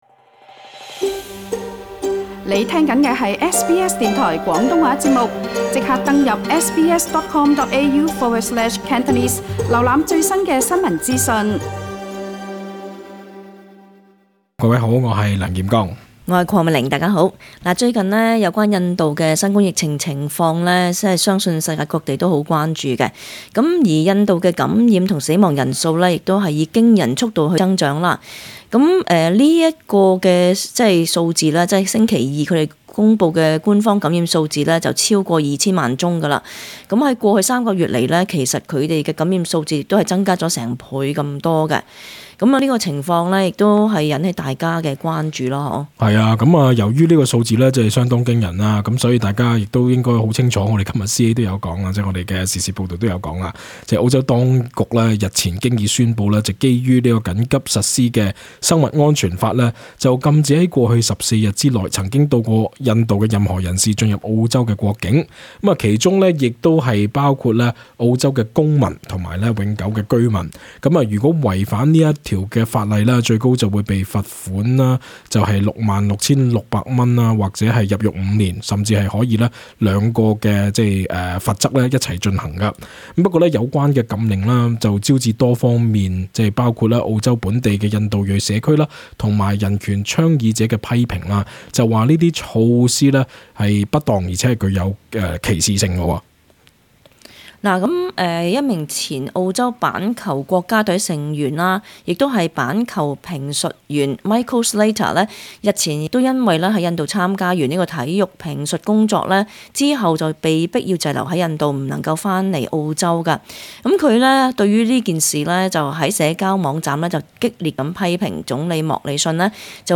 本节目内嘉宾及听众意见并不代表本台立场 READ MORE 你會否立即排隊打疫苗？